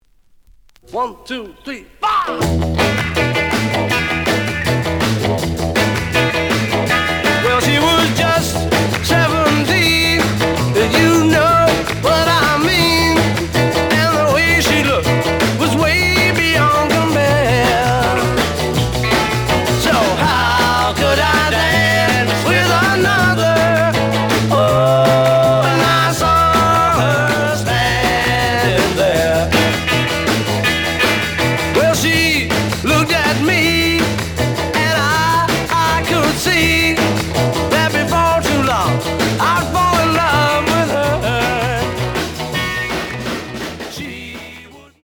The audio sample is recorded from the actual item.
●Genre: Rock / Pop
Edge warp.